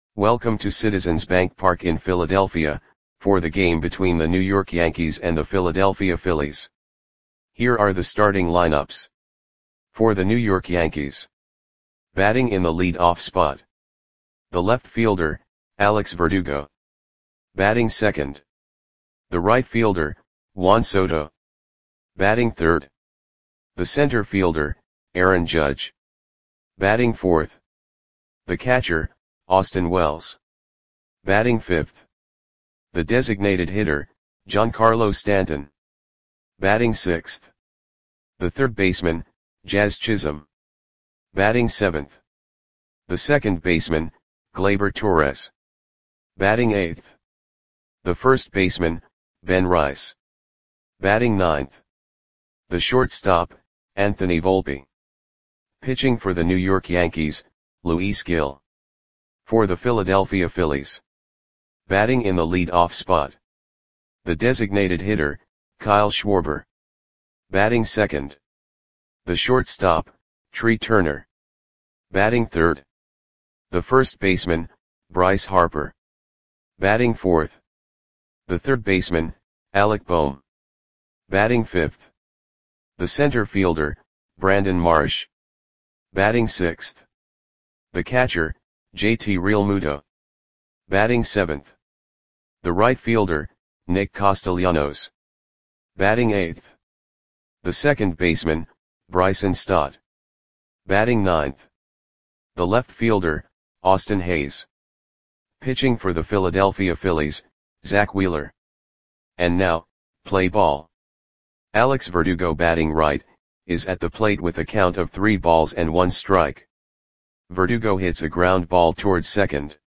Audio Play-by-Play for Philadelphia Phillies on July 29, 2024
Click the button below to listen to the audio play-by-play.